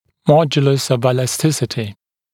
[‘mɔdjələs əv ˌɪlæs’tɪsətɪ][‘модйэлэс ов ˌилэс’тисэти]модуль упругости